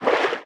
Sfx_creature_babypenguin_swim_slow_05.ogg